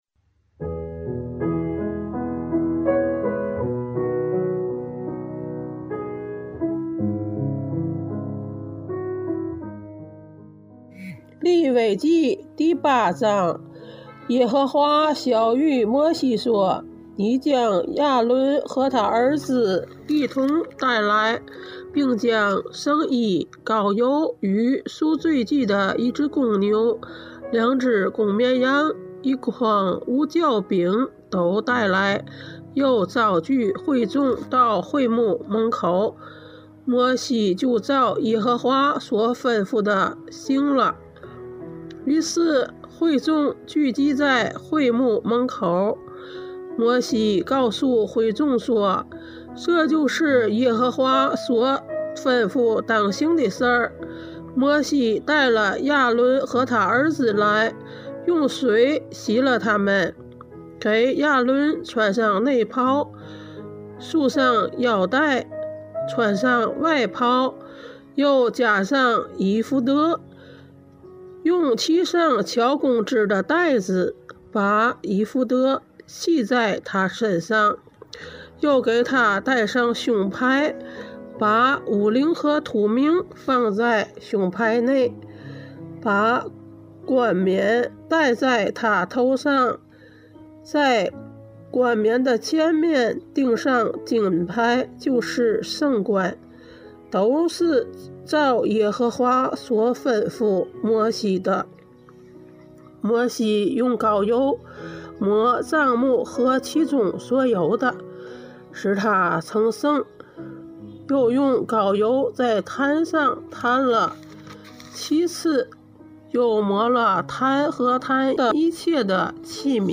读经马拉松 | 利未记8章(天津话)